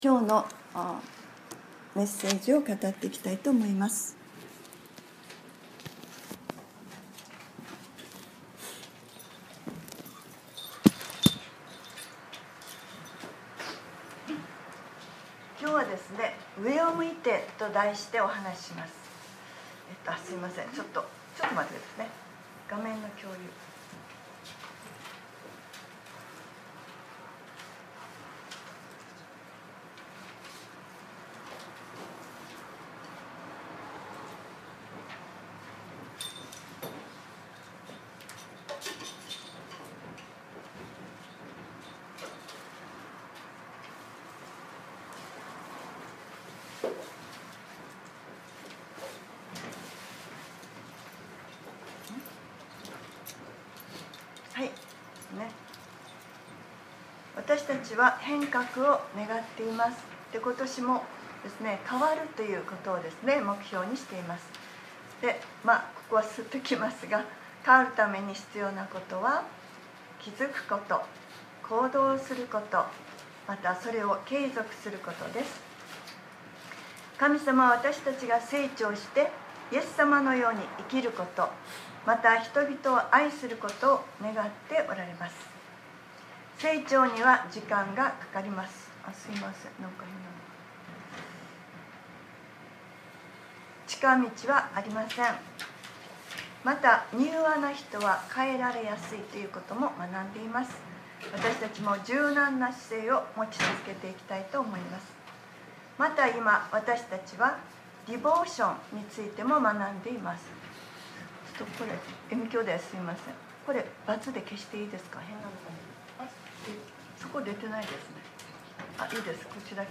2021年01月24日（日）礼拝説教『上を向いて』